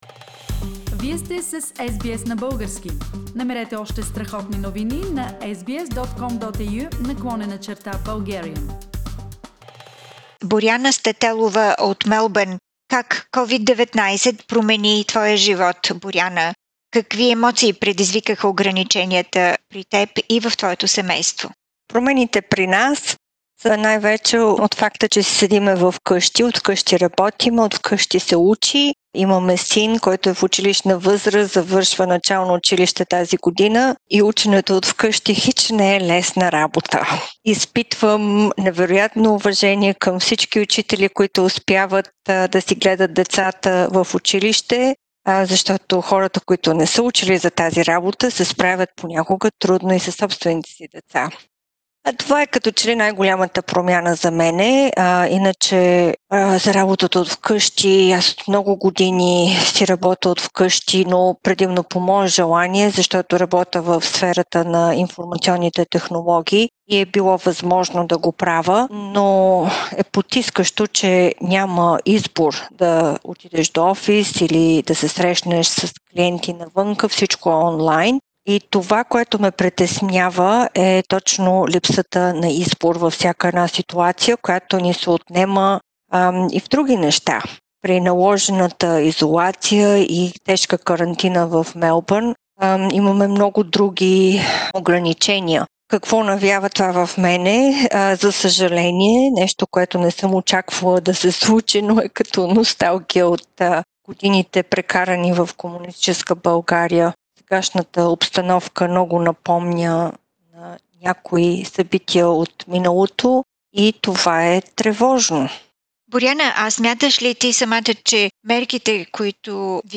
Коментар